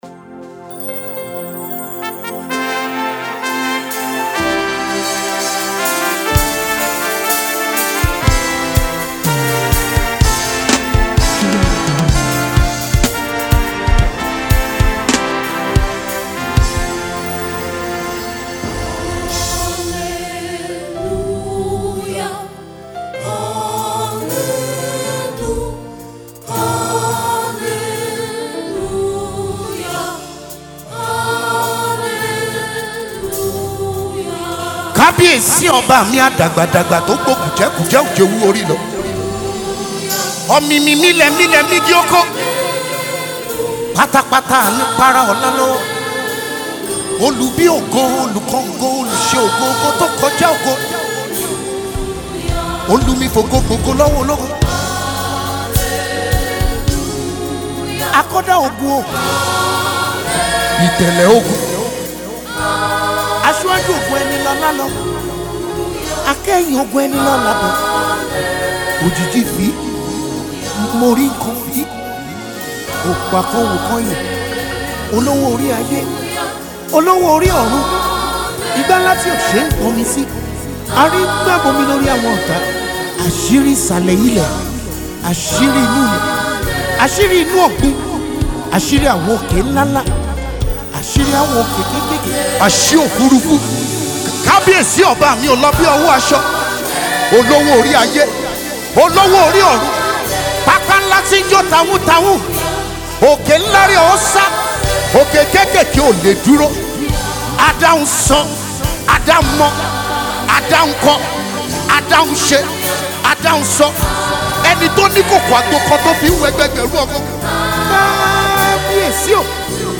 Anointed and energetic praise worship leader
Spiritual Chant
The song which was recorded live
Live Recording Concert
The song was mixed and mastered